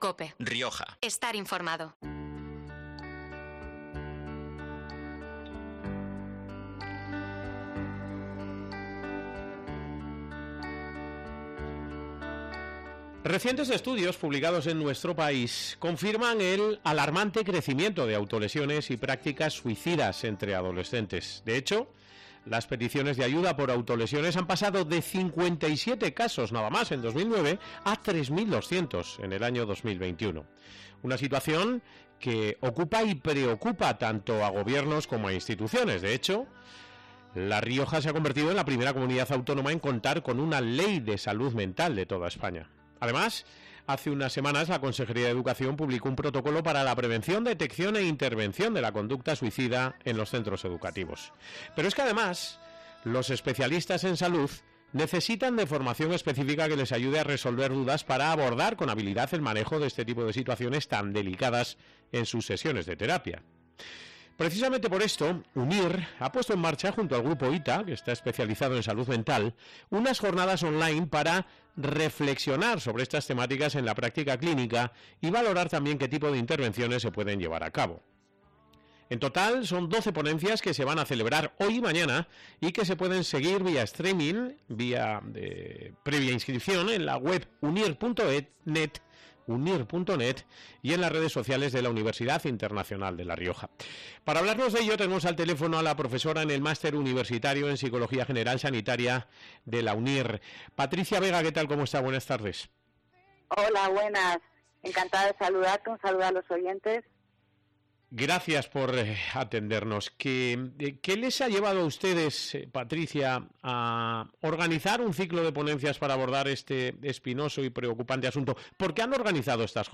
Hemos hablado de ello este miércoles en COPE Rioja